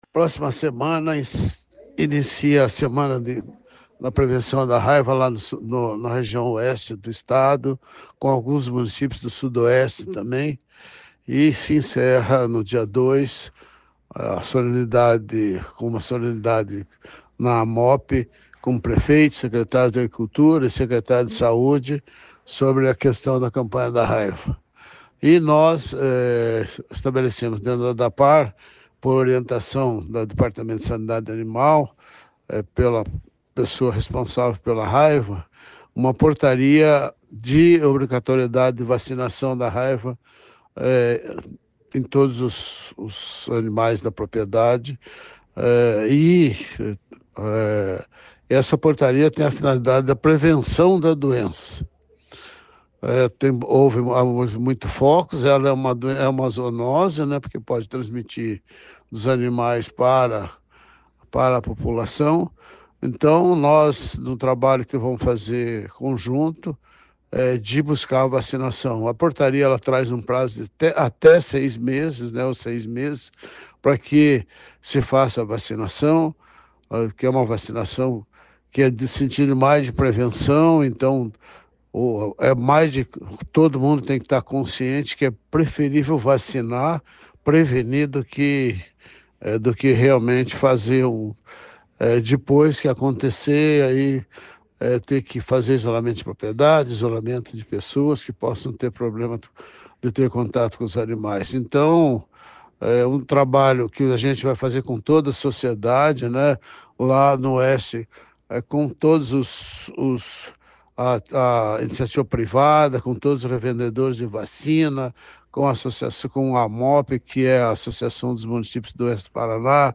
Sonora do diretor-presidente da Adapar, Otamir Martins, sobre a vacinação de herbívoros contra a raiva no Oeste do Estado